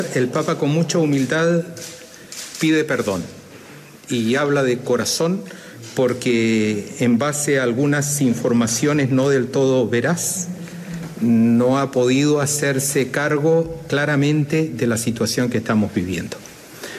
El alcance de este último movimiento del Sumo Pontífice, generó portadas en connotados medios como el New York Times en Estados Unidos, El País de España, y The Guardian en Inglaterra, donde muestran gran sorpresa ante las declaraciones de Francisco, cuyo impacto no fue menor al escuchar las palabras del Presidente de la Conferencia Episcopal de Chile, Santiago Silva.